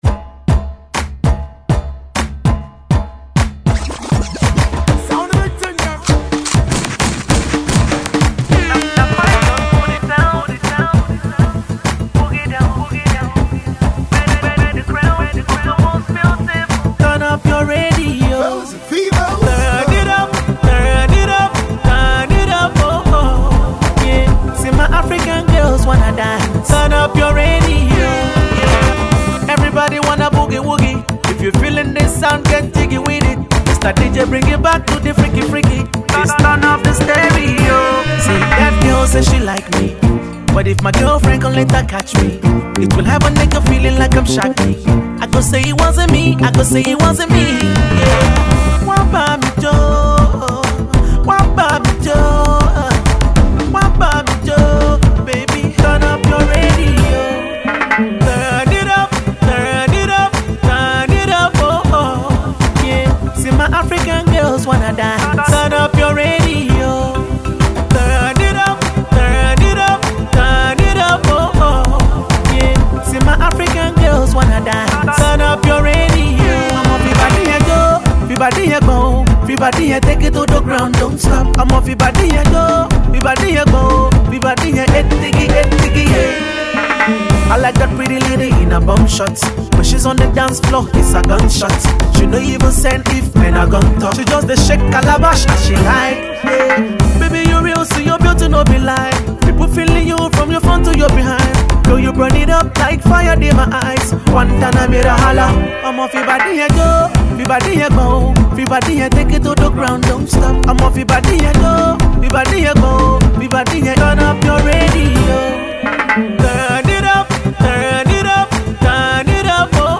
takes a Pop stab at Reggae & Dancehall